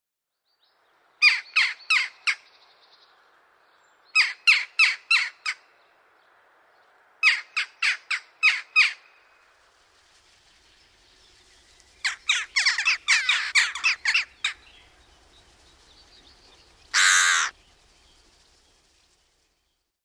Naakka
Corvus monedula Koko: 30–35 cm. Tuntomerkit: Harmaanmusta, vaalea niska, silmä miltei valkoinen.